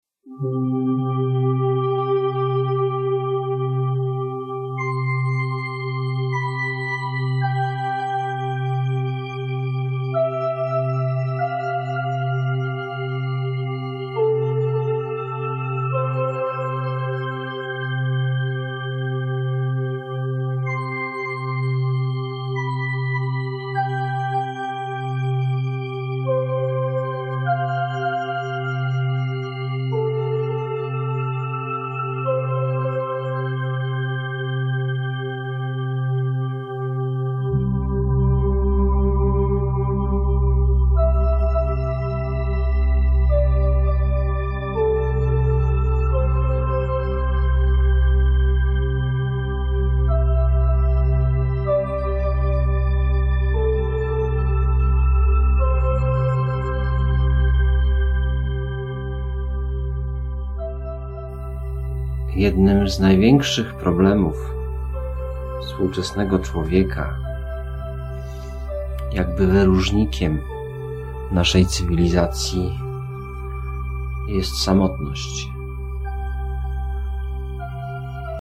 Medytacje